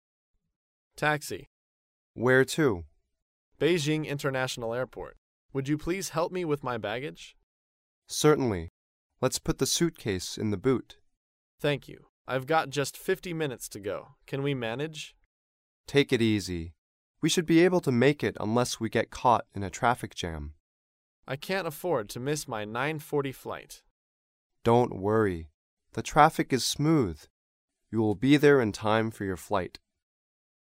高频英语口语对话 第404期:乘出租去机场 听力文件下载—在线英语听力室